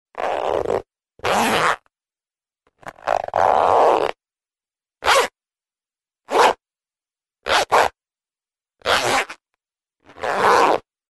Звуки молнии, одежды
На этой странице собраны звуки молний на одежде — от резких до плавных, с разными типами тканей.
Звук неловко застегивающейся молнии